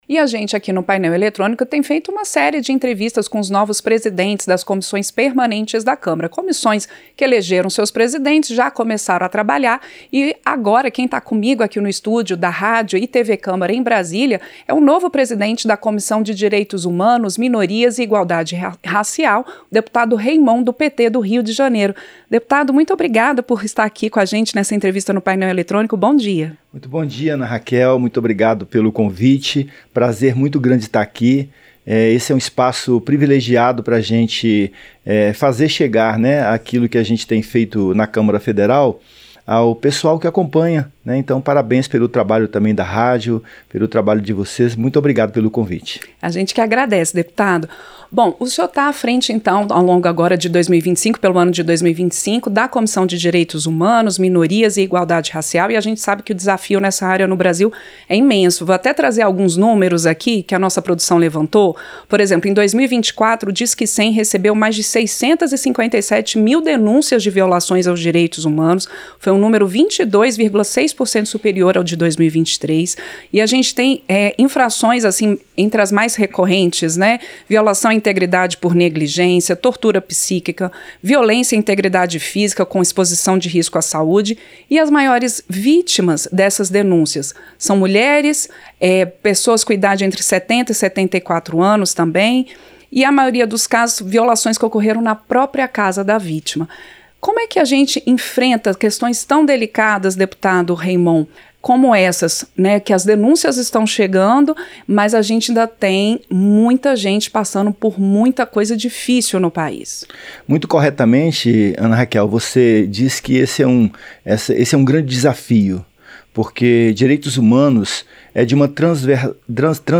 Entrevista - Dep. Reimont (PT-RJ)